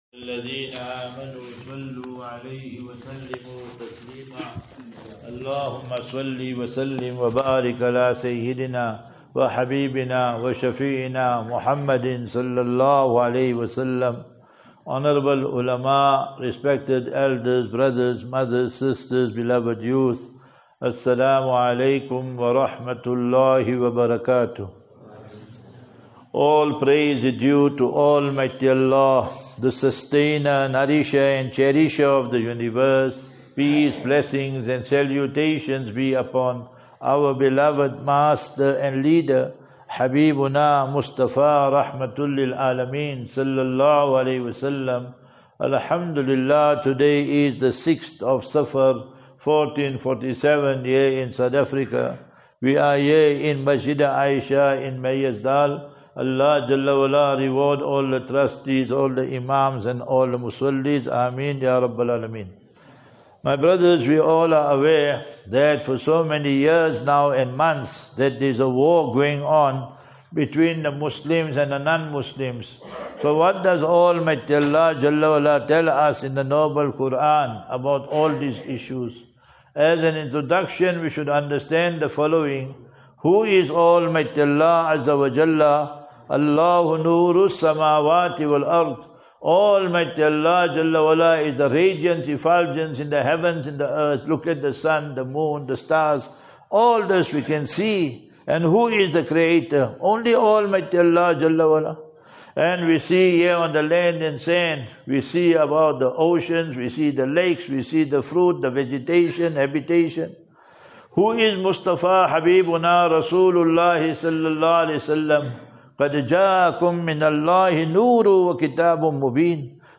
1 Aug 01 August 2025 - Jum'uah Lecture Masjid A'isha, Meyersdal